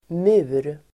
Uttal: [mu:r]